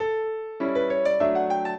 piano
minuet13-6.wav